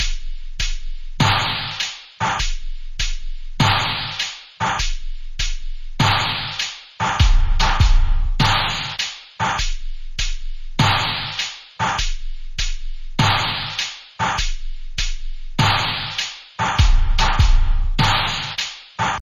怪物的震颤声音
描述：怪物的震颤声音，做出了这个令人毛骨悚然的效果。
标签： 黑暗 战争 环境音 恐怖 闹鬼 怪异 震颤 怪物 可怕 喇叭
声道立体声